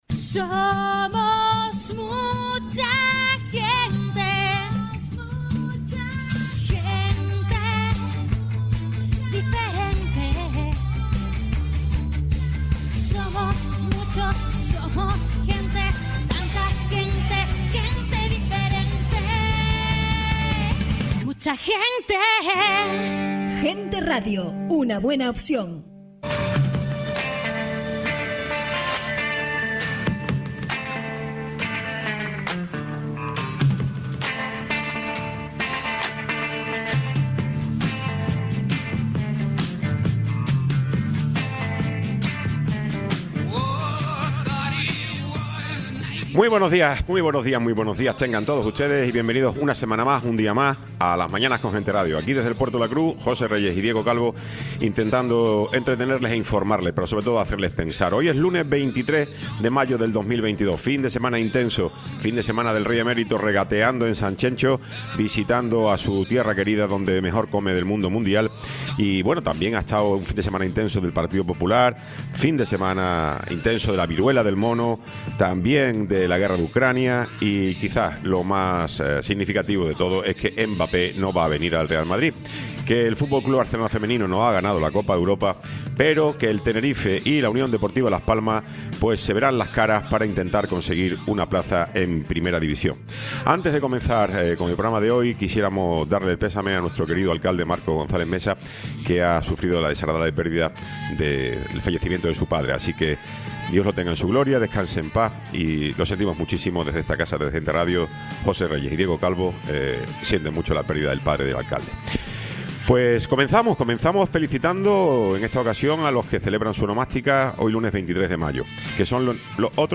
Tiempo de entrevista con José Alberto Díaz Estébanez, diputado autonómico CC
Tertulia